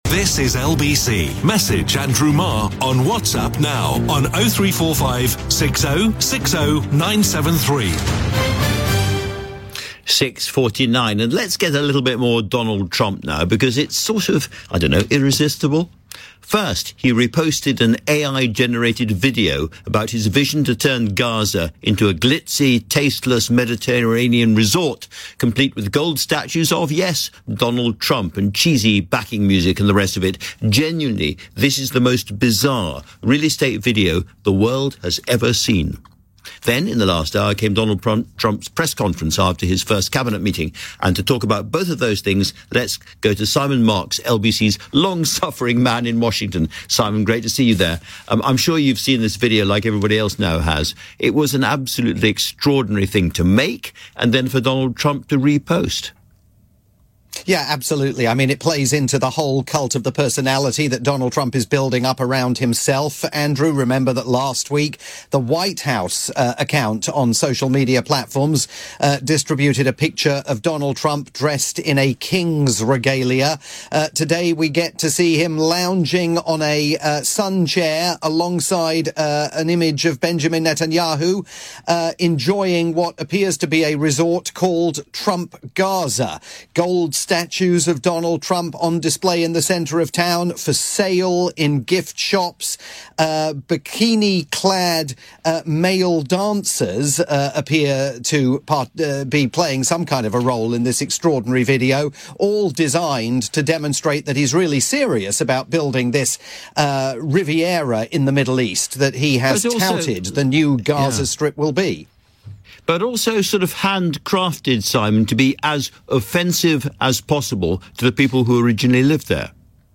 live update for "Tonight with Andrew Marr" on the UK's LBC.